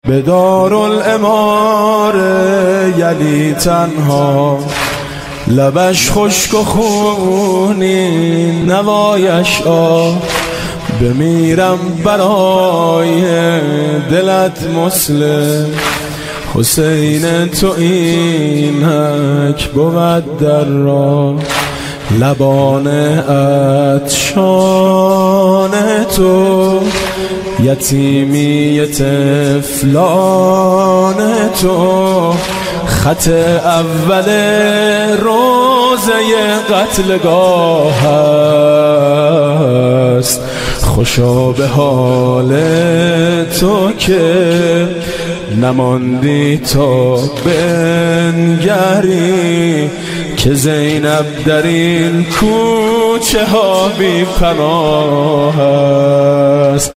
زنگ موبایل(باکلام)
به مناسبت ایام عزای حسینی(علیه السلام)